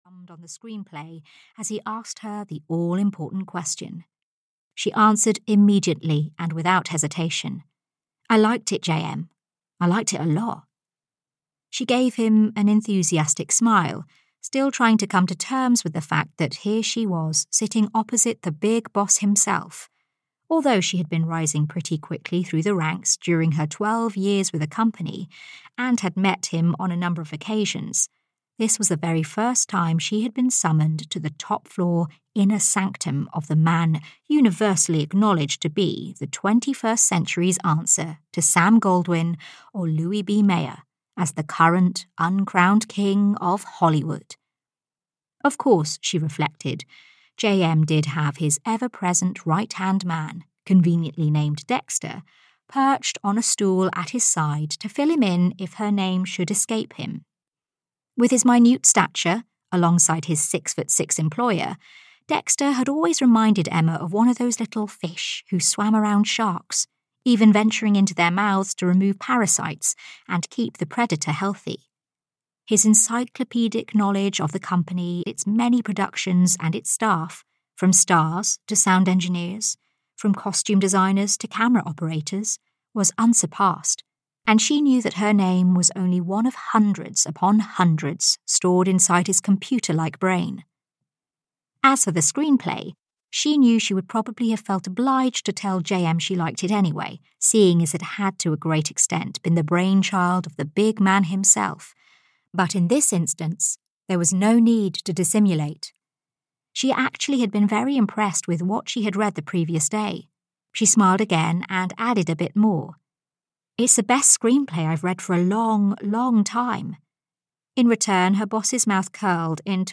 Dreaming of Italy (EN) audiokniha
Ukázka z knihy